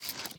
minecraft / sounds / mob / panda / eat12.ogg
eat12.ogg